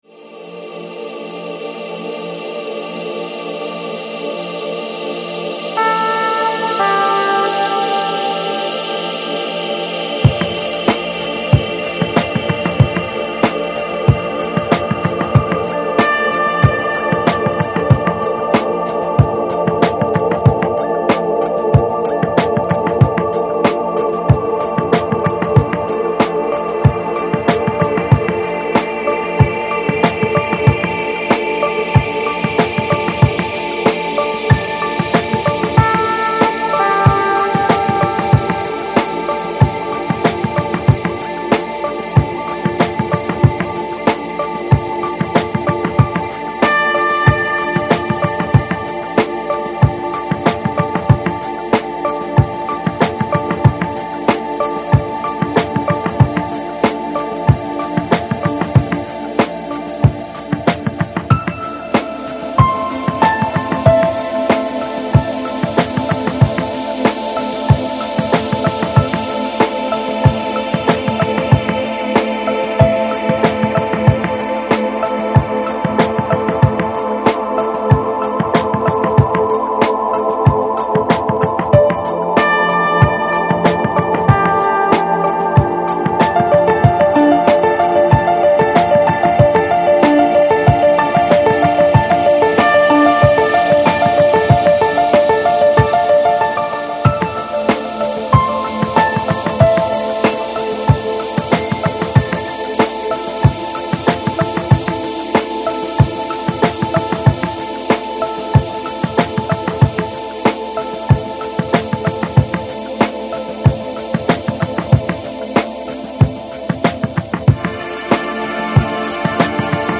Жанр: Lounge, Chill Out, Ambient